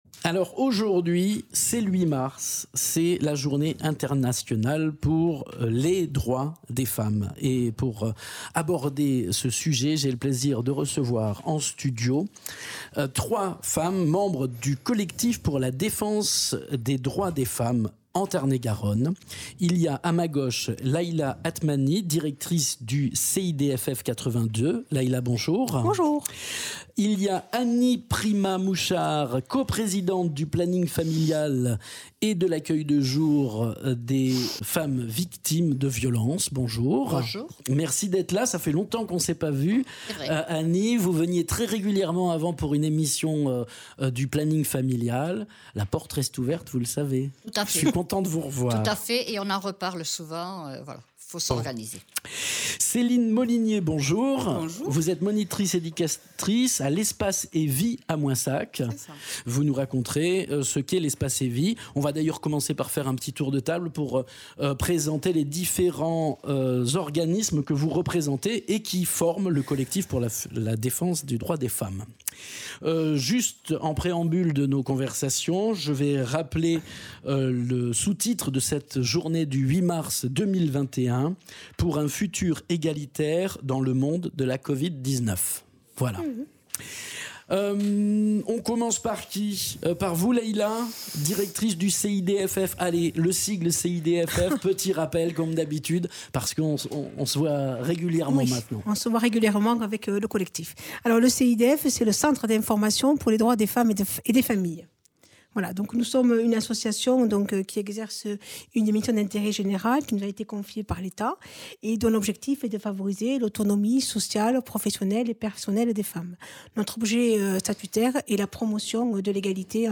Trois membres du collectif pour la défense des droits des femmes en Tarn-et-Garonne sont invitées pour la journée internationale des droits des femmes. Elles expliquent leurs actions mises en place dans le département.
Interviews